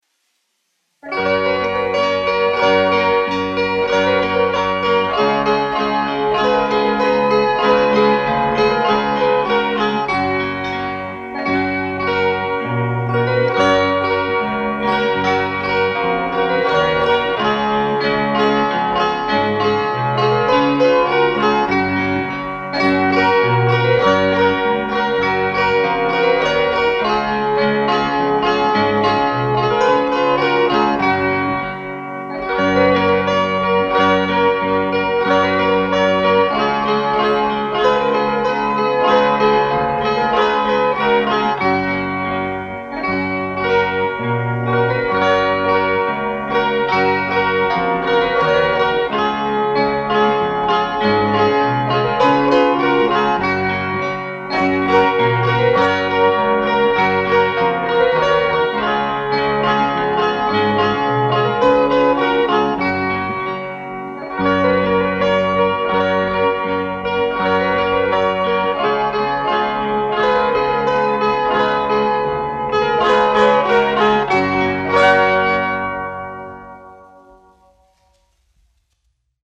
Alsung polka : Lettish folk dance
1 skpl. : analogs, 78 apgr/min, mono ; 25 cm
Polkas
Kokļu ansambļi
Latviešu tautas dejas
Latvijas vēsturiskie šellaka skaņuplašu ieraksti (Kolekcija)